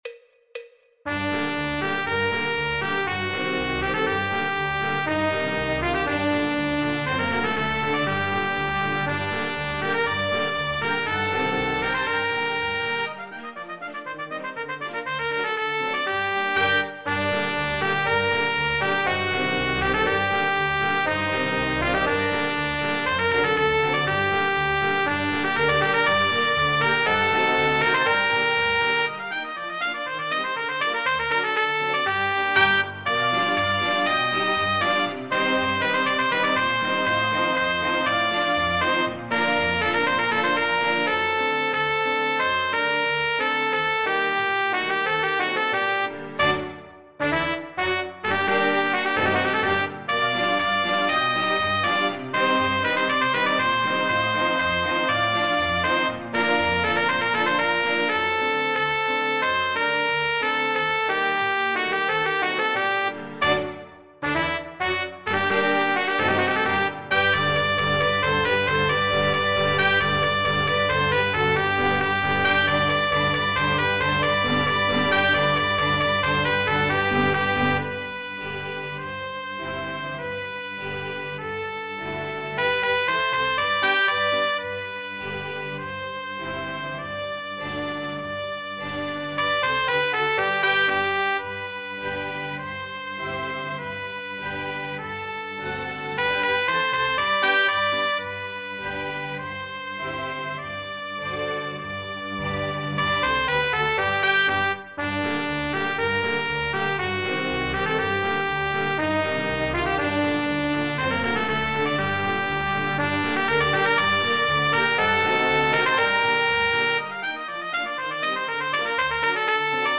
El MIDI tiene la base instrumental de acompañamiento.
Popular/Tradicional